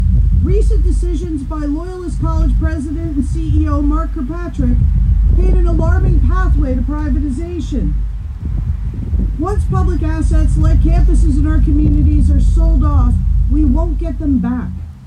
Hundreds of OPSEU members from the Quinte area’s post secondary institution, St Lawrence College in Kingston, and Algonquin College in Ottawa were also on hand.